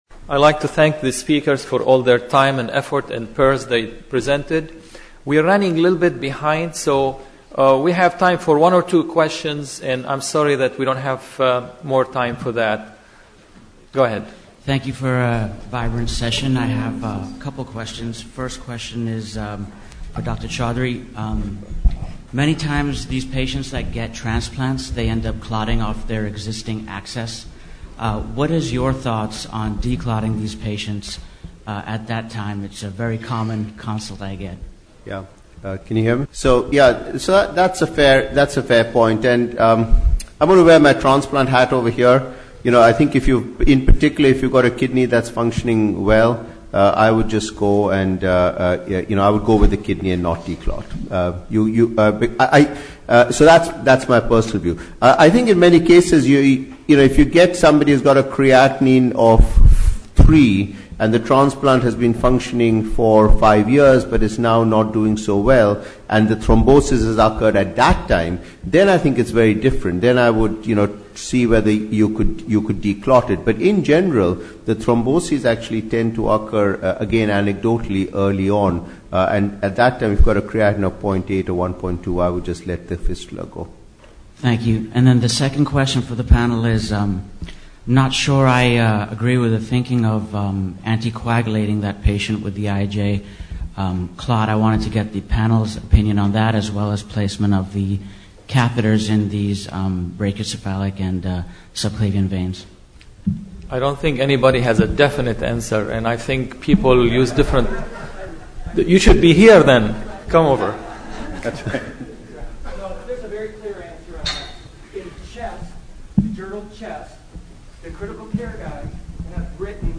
HDCN - 2013 ASDIN Annual Scientific Meeting
Q and A - Discussion